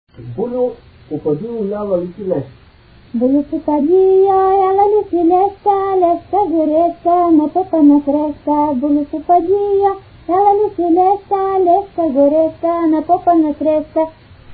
музикална класификация Песен
размер Две четвърти
фактура Едногласна
начин на изпълнение Солово изпълнение на песен
битова функция На хоро
фолклорна област Югоизточна България (Източна Тракия с Подбалкана и Средна гора)
начин на записване Магнетофонна лента